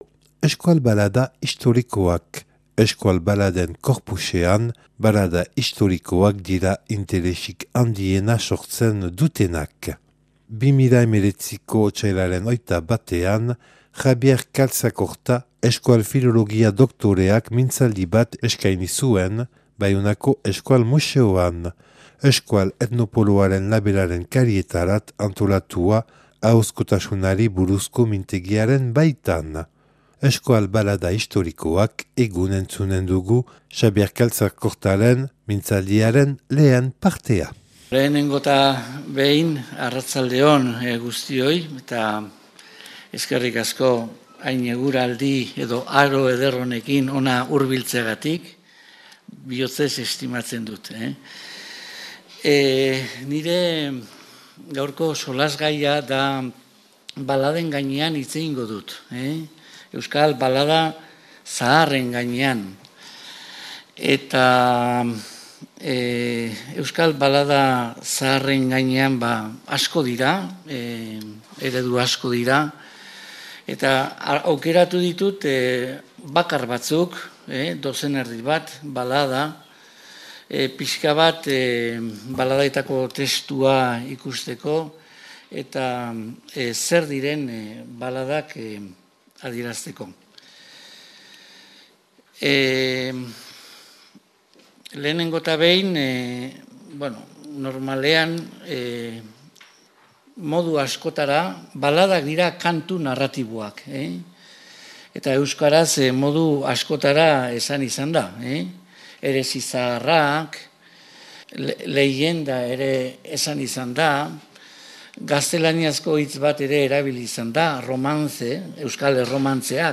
Otsailaren 21an Baionako Euskal Museoan, Euskal etnopoloaren mintzaldia).